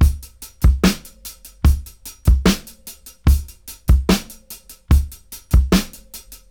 73-DRY-06.wav